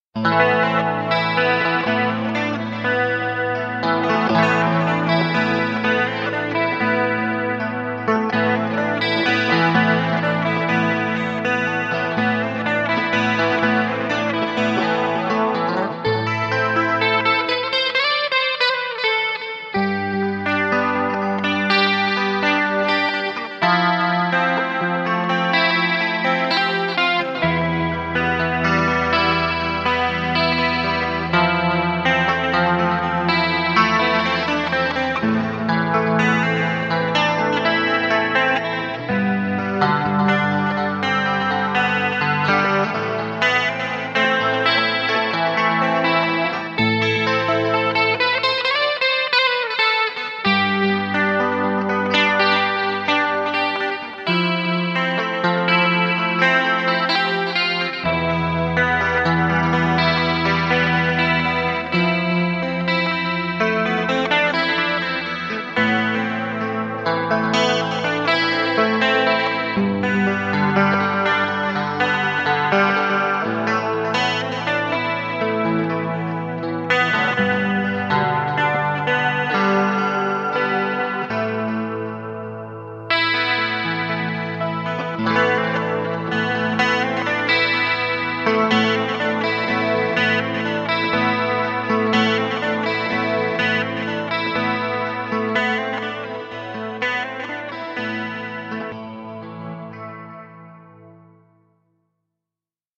Примеры музыки для исполнения на электрогитаре